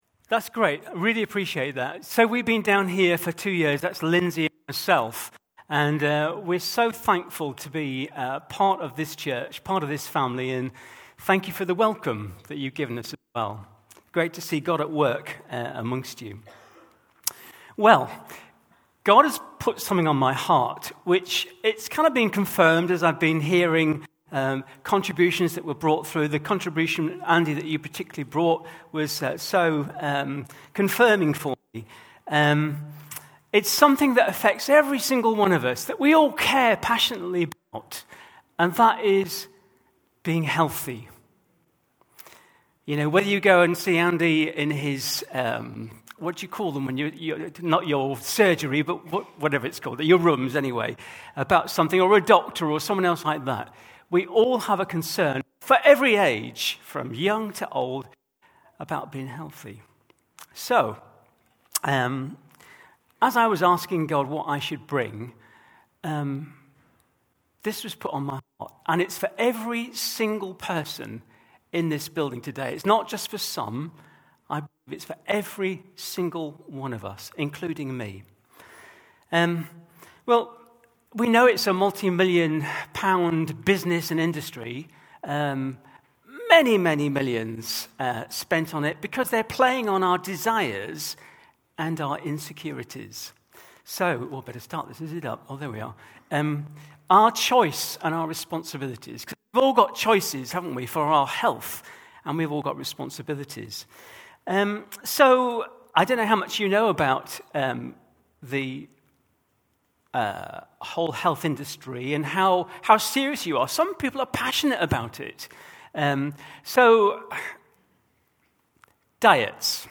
Grace Church Sunday Teaching